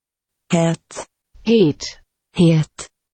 Samska HET [he:t]